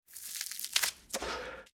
Глубокий затяг в легкие этого дурманящего вещества